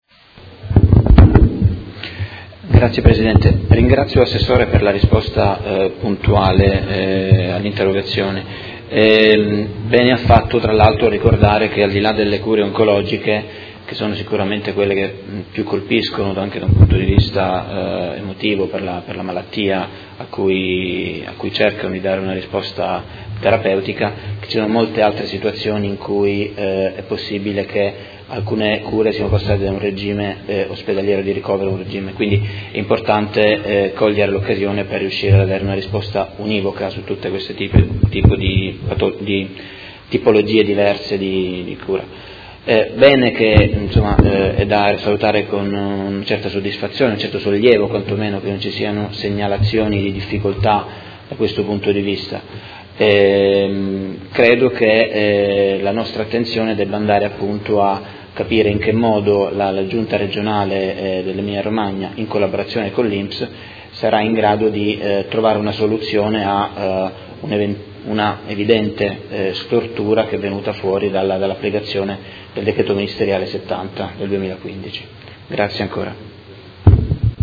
Seduta del 17/05/2018. Conclude interrogazione del Consigliere Fasano (PD) avente per oggetto: Copertura economica per lavoratori sottoposti a cure oncologiche